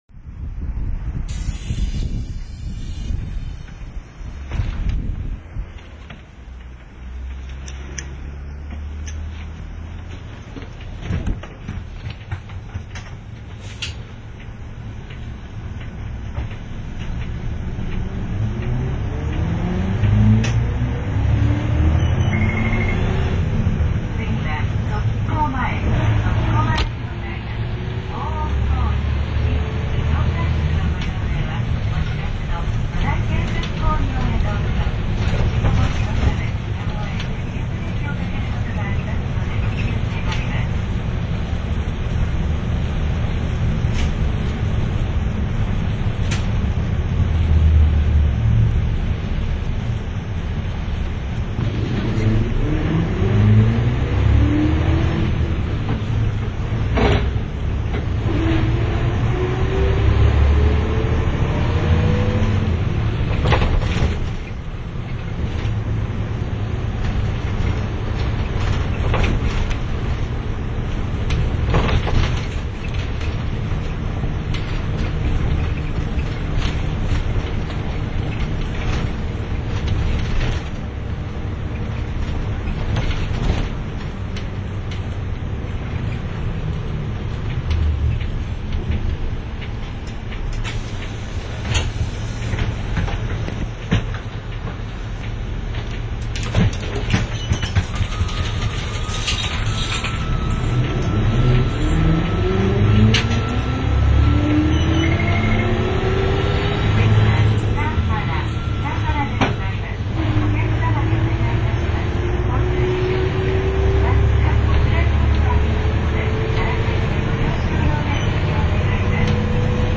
モノコックボディのＣＪＭの走行音です。
この時代のロッドシフト特有のギア鳴り音をお楽しみ下さい！
綾５２系統（当時は系統番号なし） 富士塚〜望地 （７０３ＫＢ）